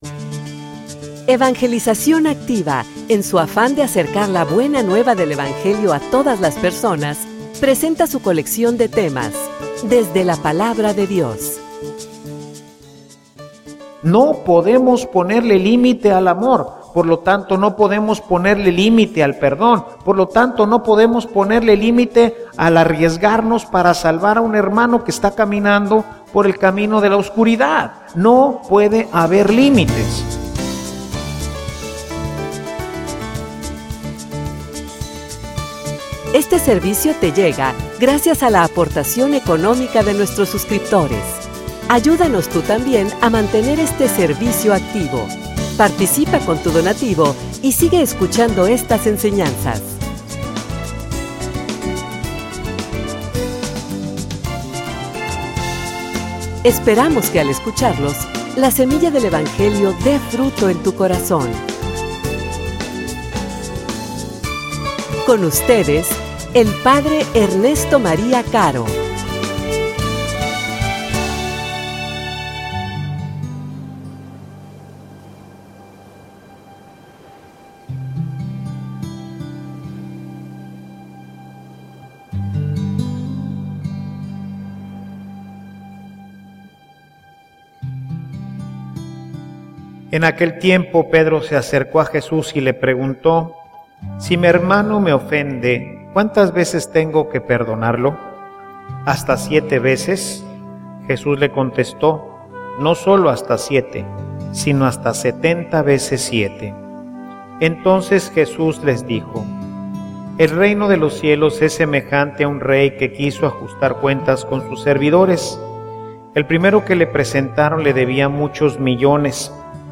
homilia_El_amor_no_pone_limites.mp3